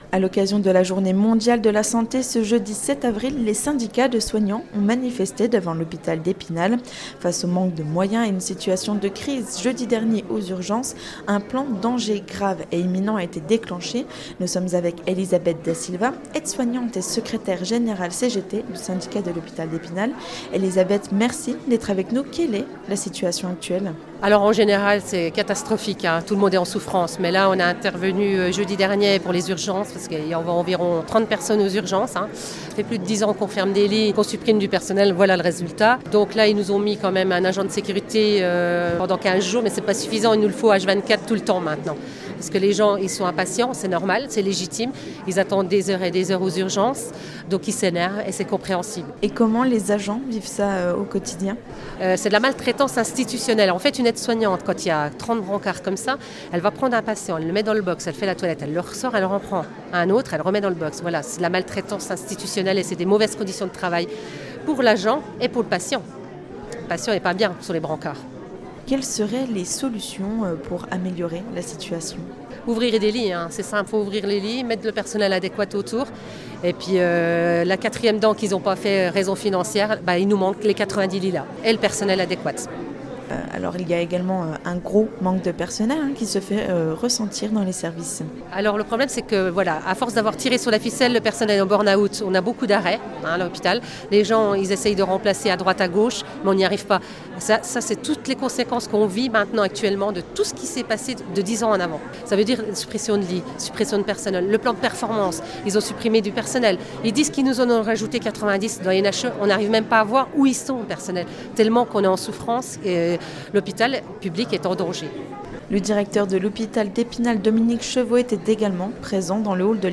Reportage VOSGES FM en podcast